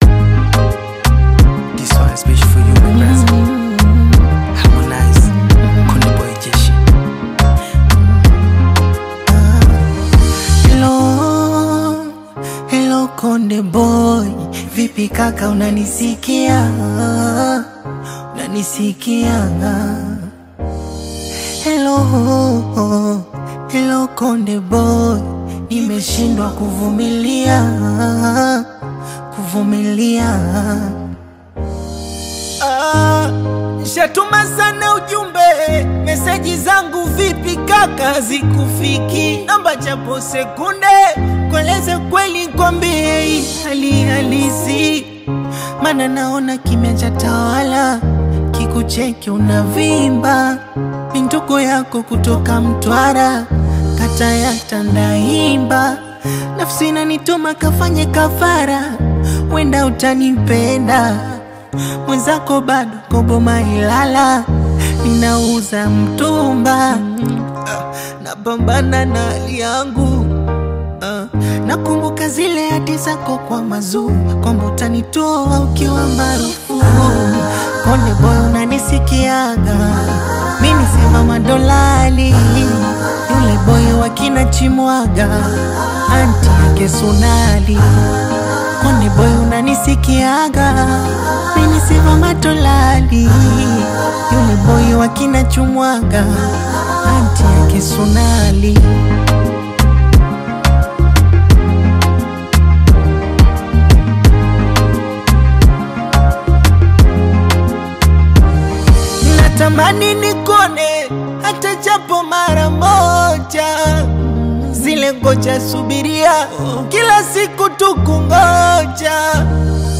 soulful R&B/Afro-urban single
Genre: Bongo Flava